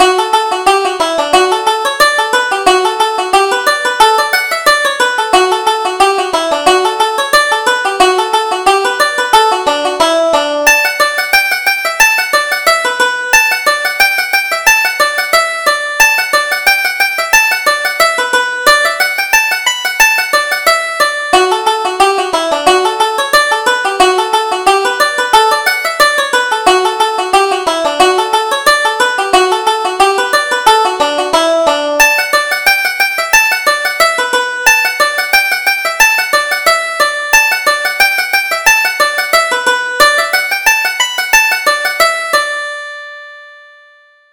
Reel: The Hornless Cow